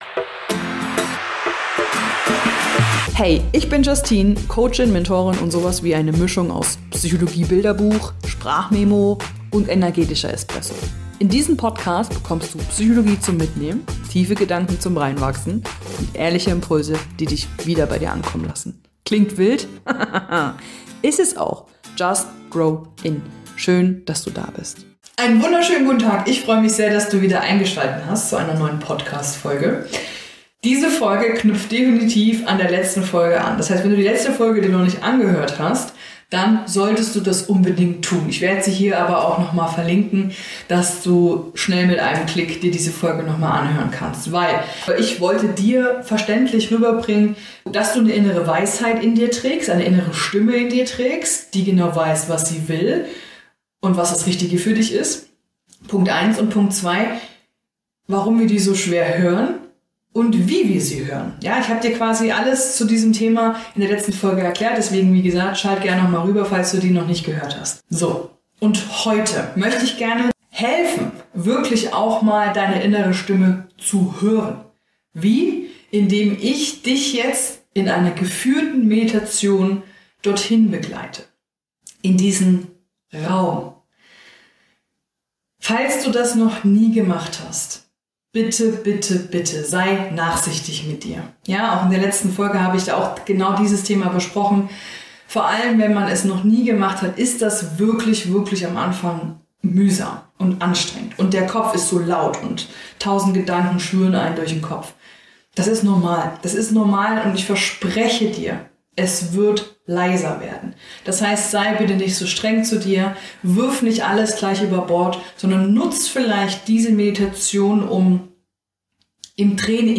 Diese geführte Meditation hilft dir, wieder bei dir anzukommen, deine innere Weisheit zu hören und die Stimme in dir wahrzunehmen, die ruhig, klar und liebevoll ist.